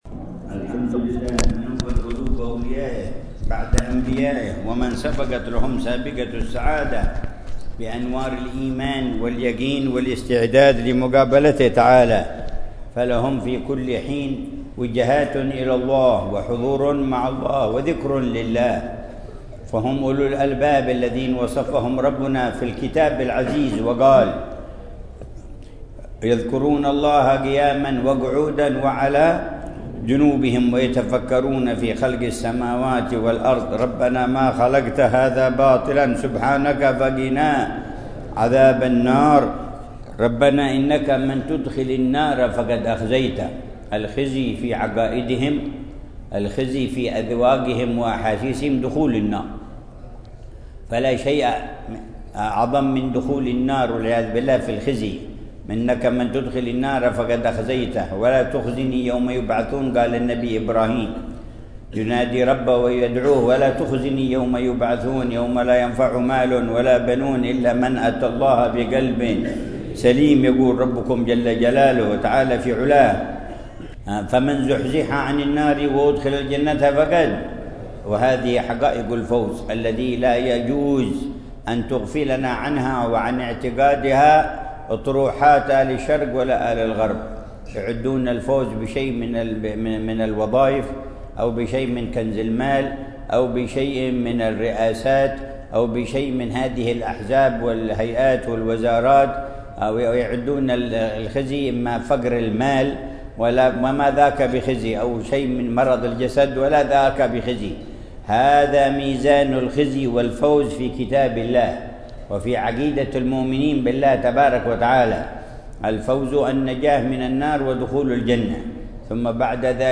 مذاكرة العلامة الحبيب عمر بن محمد بن حفيظ في مسجد العبادة، بحارة عيديد القبلية، بمدينة تريم، ليلة الثلاثاء 14 رجب الأصب 1446هـ بعنوان: